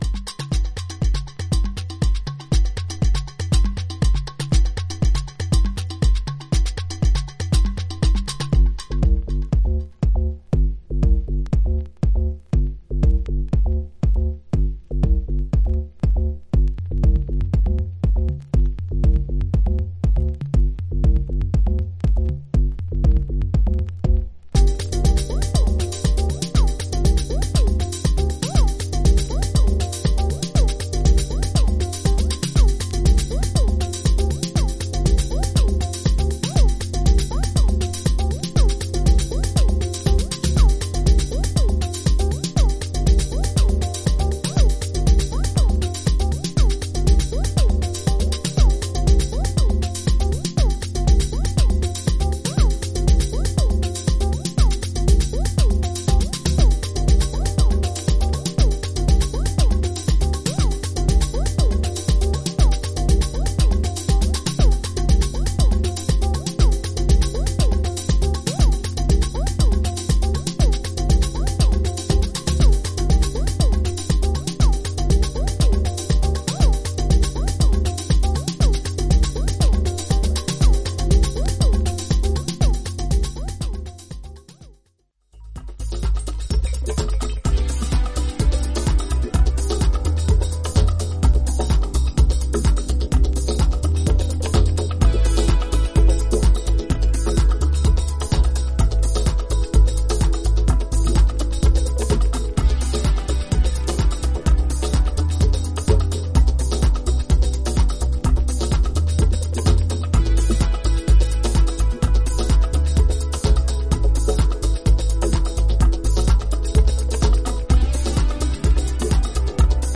スペーシーなディープ・ハウス・ナンバー
浮遊感が気持ち良い